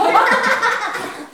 rire-foule_02.wav